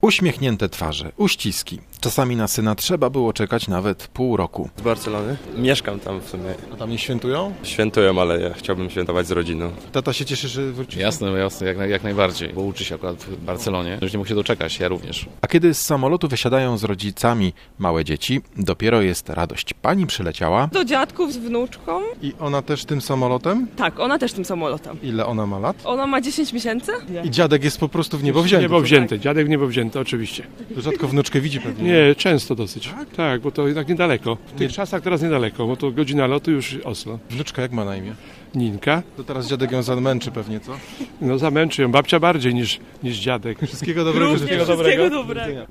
W Poznaniu na Ławicy w hali przylotów było bardzo ciasno. Był tam też nasz reporter.